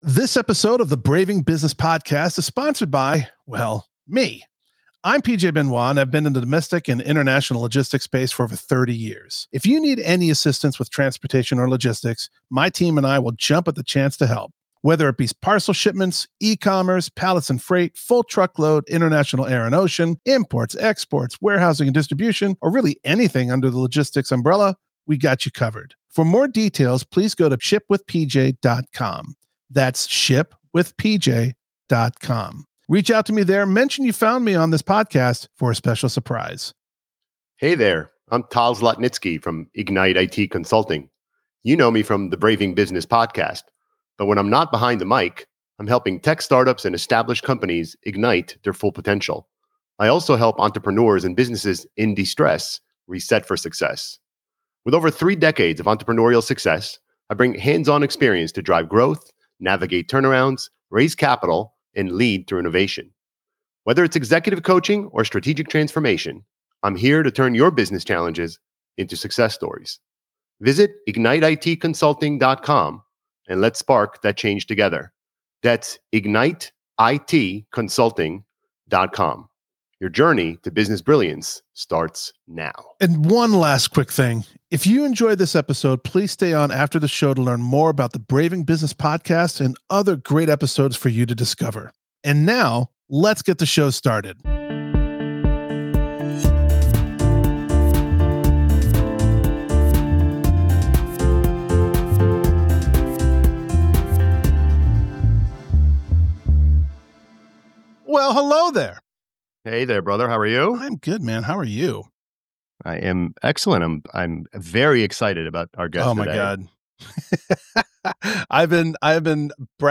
Join us on this episode of Braving Business as we delve into the world of politics, resilience, and leadership with Adam Kinzinger, a former U.S. Congressman and current senior political commentator at CNN.